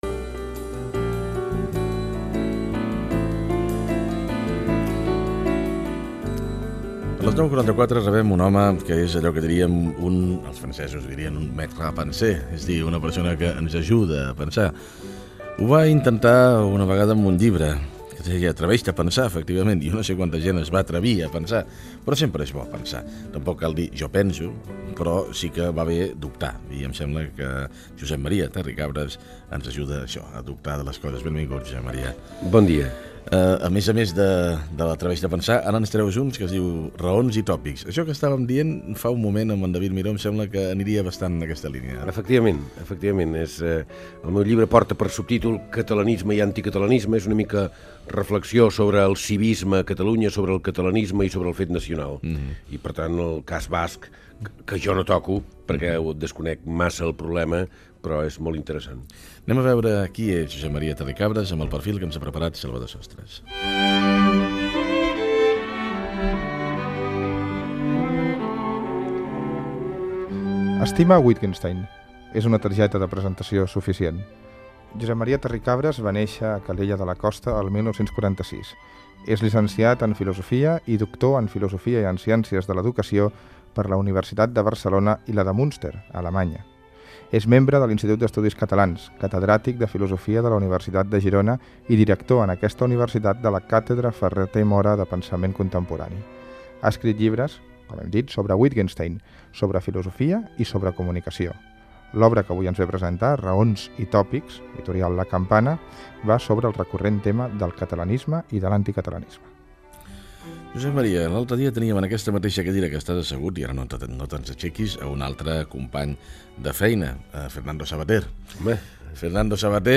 Fragment d'una entevista al filòsof Josep Maria Terricabras (1946-2024) amb motiu de la publicació del seu llibre "Raons i tòpics".
Info-entreteniment